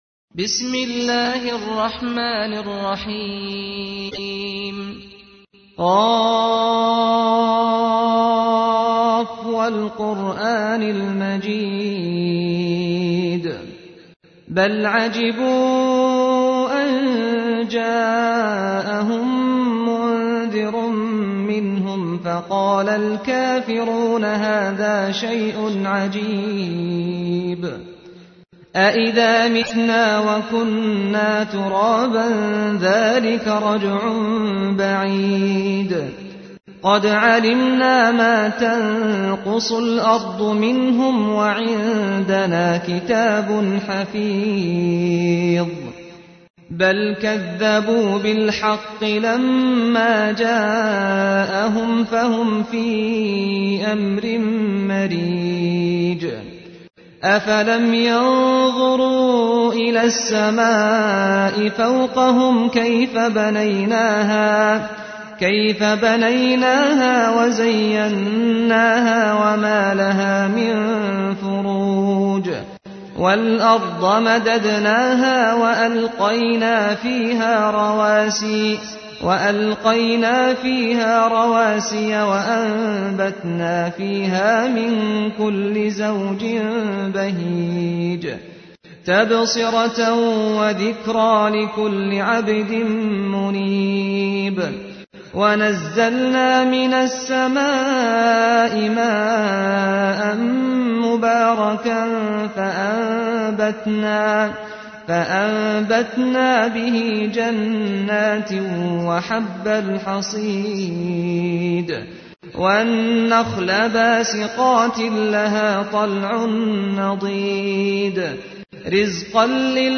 تحميل : 50. سورة ق / القارئ سعد الغامدي / القرآن الكريم / موقع يا حسين